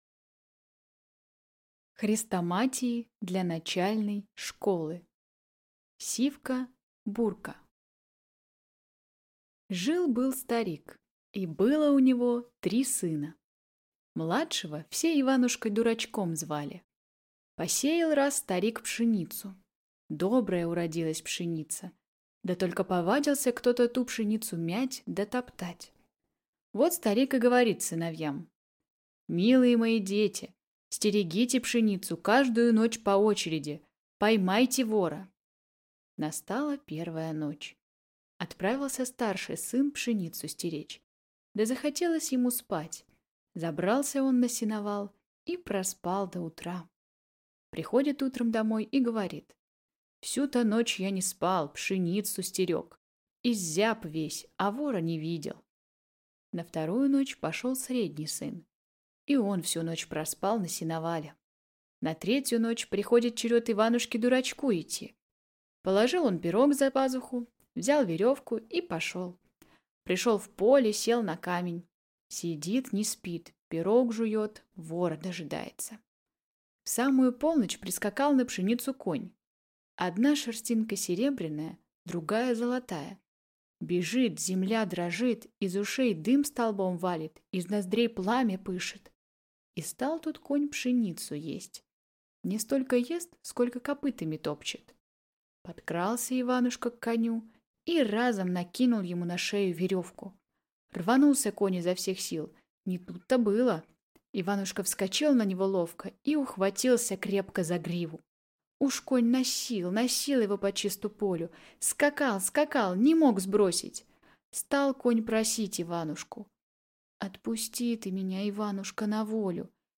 Аудиокнига Сивка-бурка